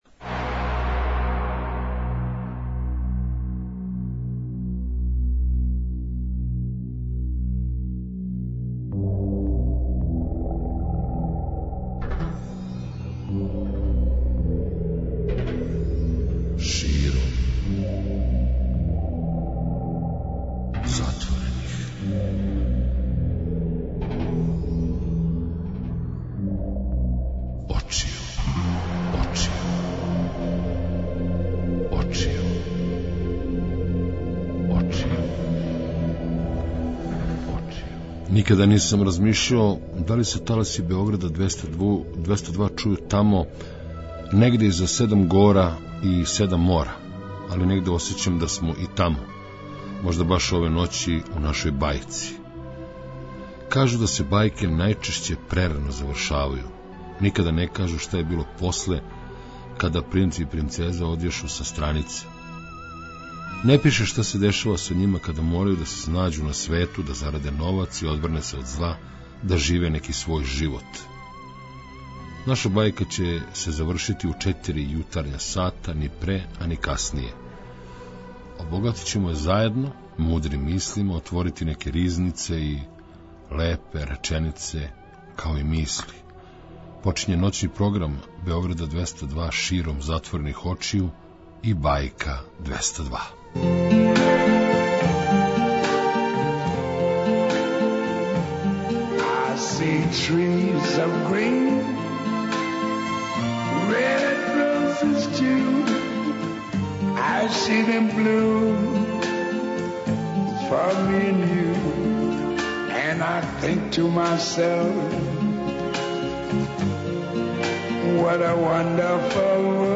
преузми : 54.75 MB Широм затворених очију Autor: Београд 202 Ноћни програм Београда 202 [ детаљније ] Све епизоде серијала Београд 202 Састанак наше радијске заједнице We care about disco!!!